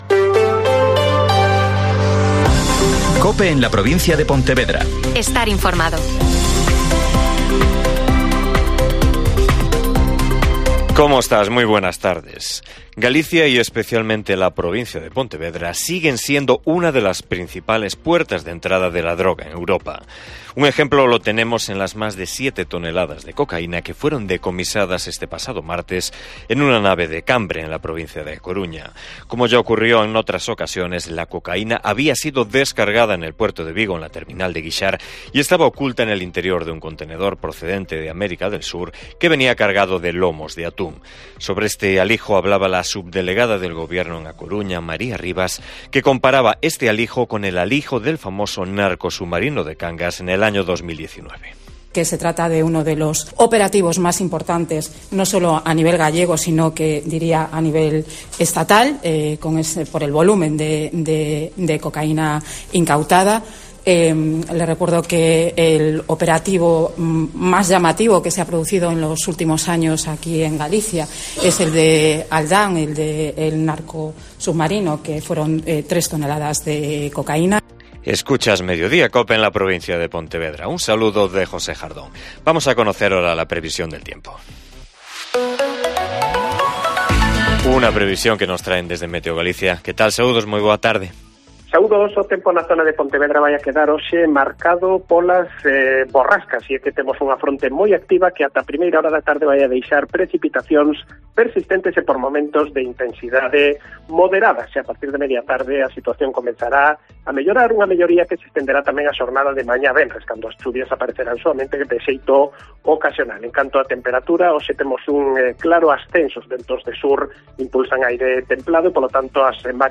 Mediodía COPE Pontevedra Y COPE Ría de Arosa (Informativo 14:20h)